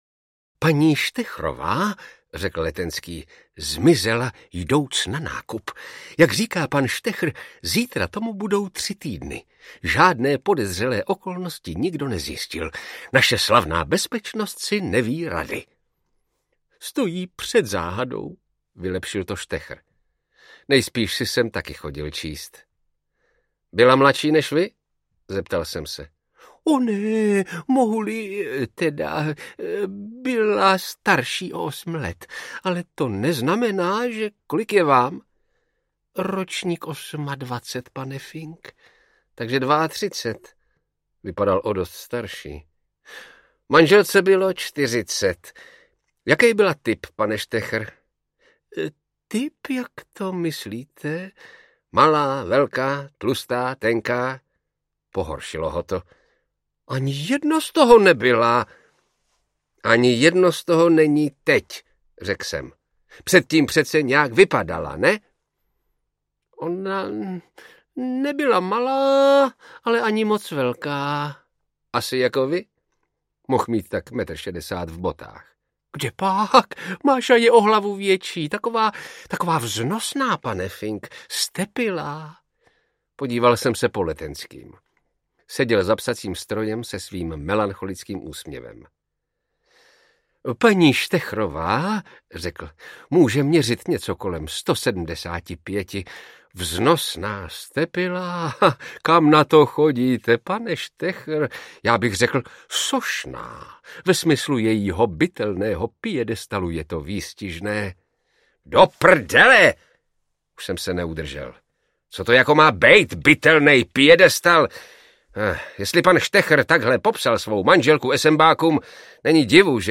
Velice dlouhé schody audiokniha
Ukázka z knihy
Vyrobilo studio Soundguru.